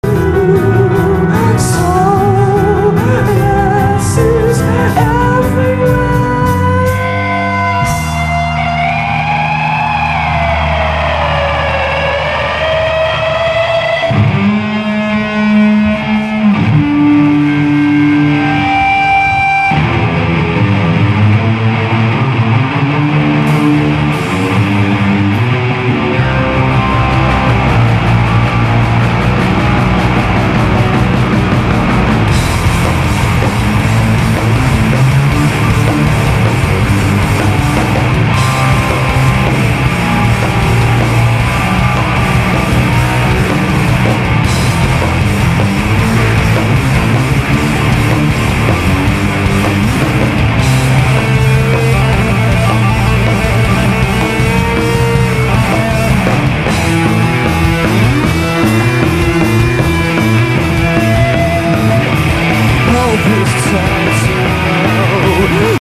извиняюсь за плохое качество, звук вырезан из видеоклипа
на студийной записи звук не такой